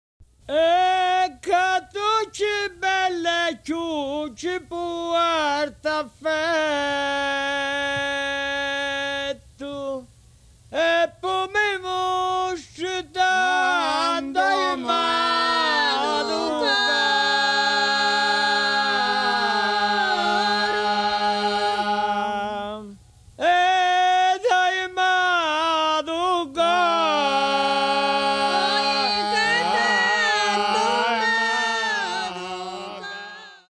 The Calabrian bagpipe, unique in Europe, has two chanters, and two drones on a single stock, and the bag (held in front of the body) is made of a whole sheepskin.
The wooden oboe (ciaramella or piffero) plays the high part of the melody. The following was recorded at the Smithsonian Folklife Festival in Washington, D.C. The bagpipers
chimes and percussion